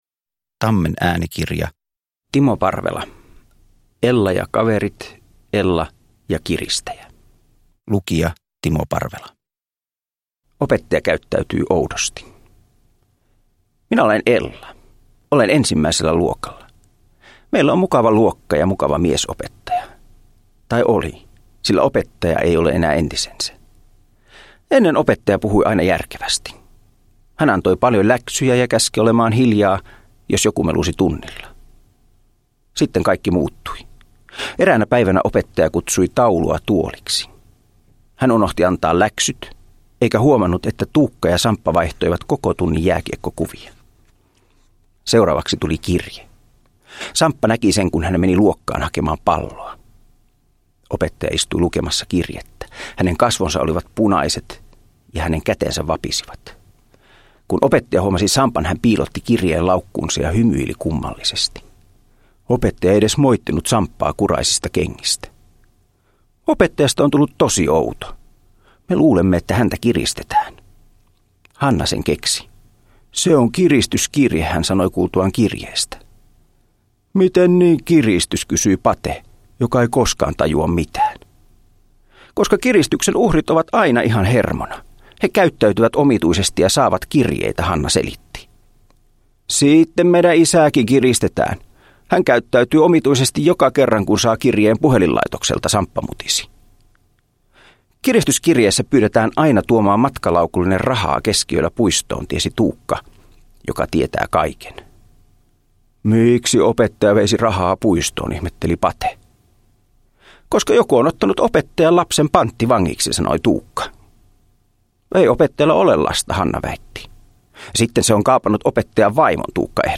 Ella ja kiristäjä – Ljudbok
Uppläsare: Timo Parvela